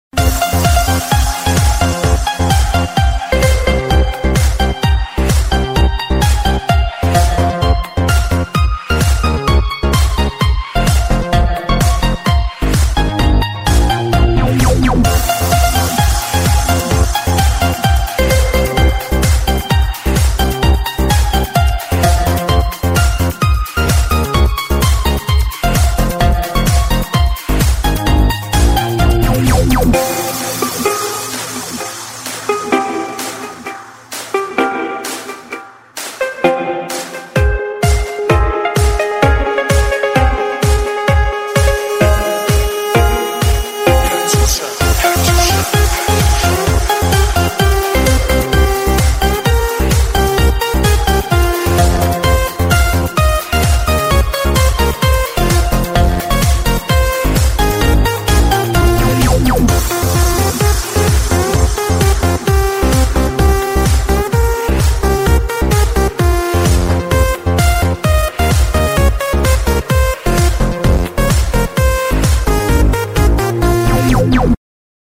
прикольные